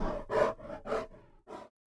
WAV · 81 KB · 單聲道 (1ch)